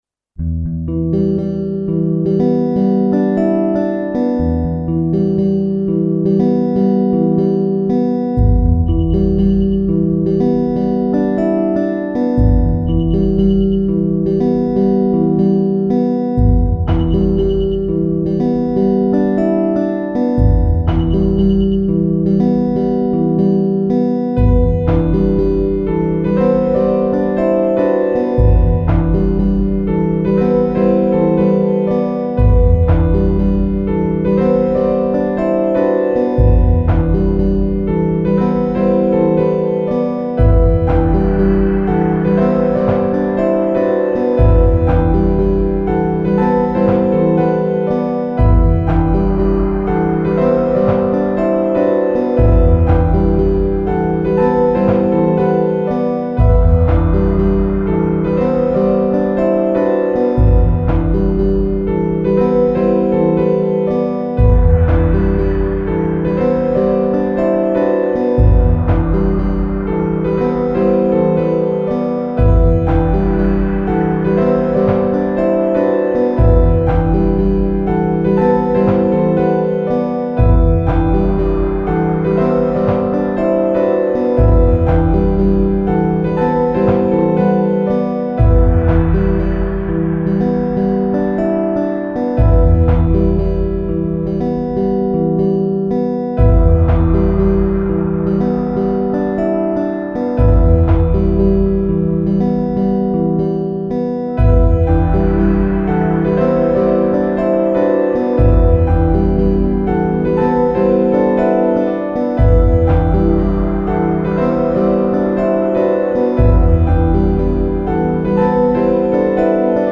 Elegant piano.